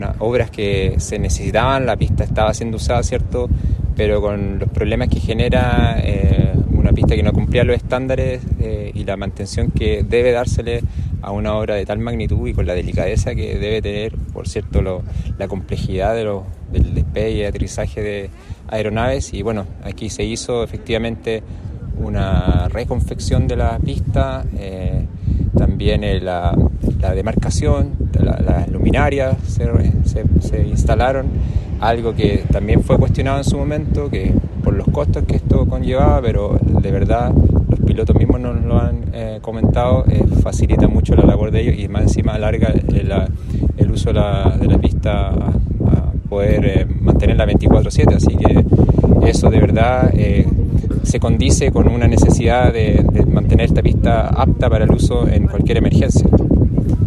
Respecto a esta importante inversión, el SEREMI de Obras Públicas de la Región de Coquimbo, Javier Sandoval, señaló que
SEREMI-Obras-Publicas-Javier-Sandoval.mp3